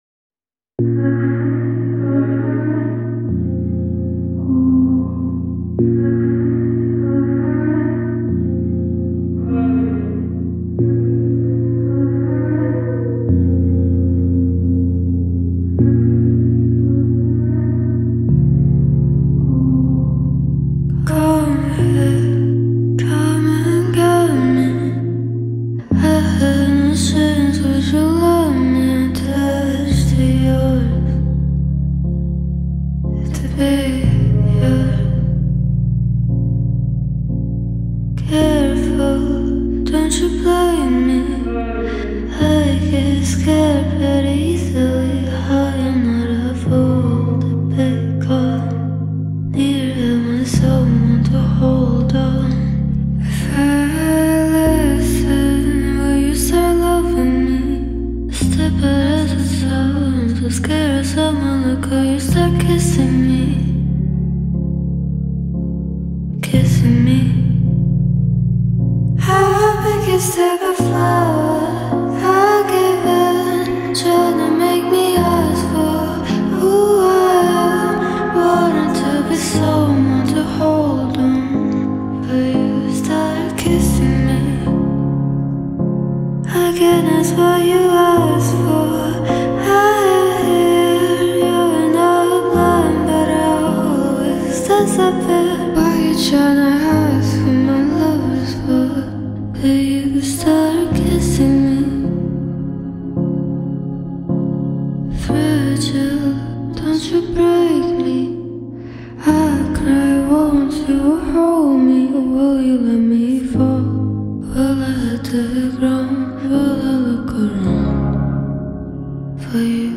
погружает слушателей в атмосферу нежных эмоций и романтики